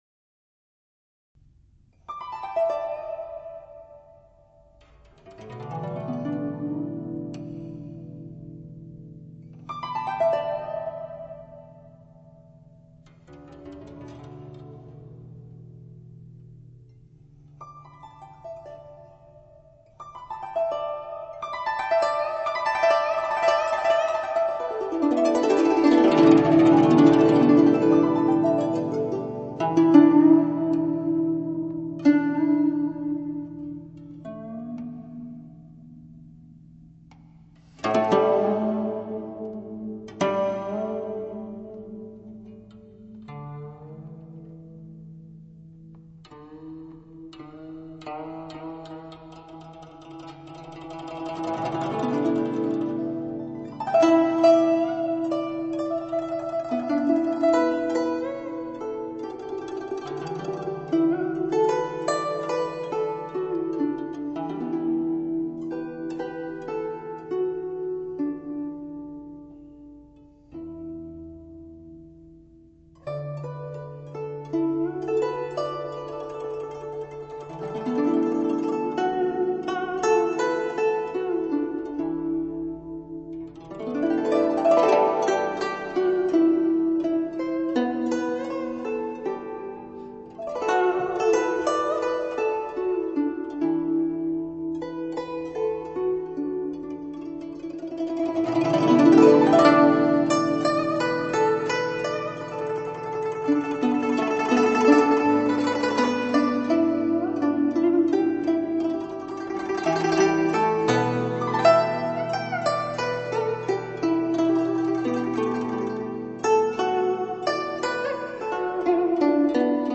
整碟的重新混音效果非常均衡
调音台：SONY DMX-R1000
麦克风：NEUMANN-M147 AKG C12VR